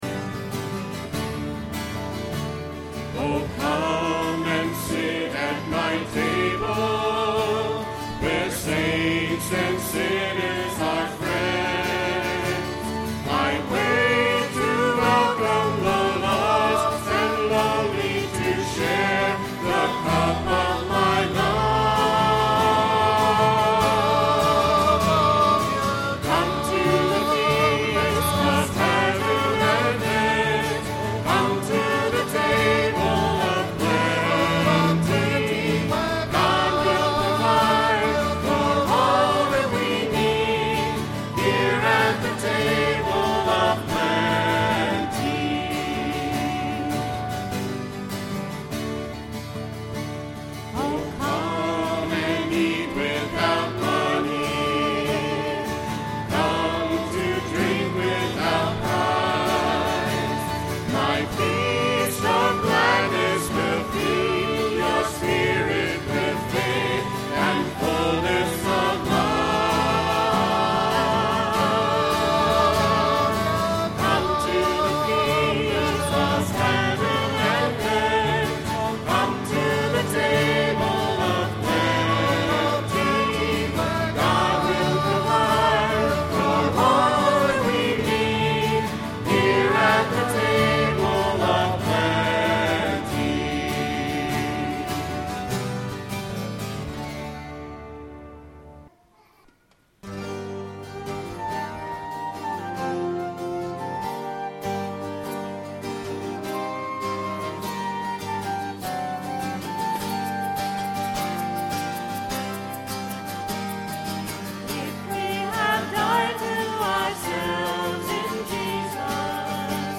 10/04/09 10:30 Mass Recording of Music - BK1030
Note that all spoken parts of the Mass have been removed from this sequence. Music for Mass 100409 -- Note that the recorder was turned on late so the beginning of the entrance song is missing.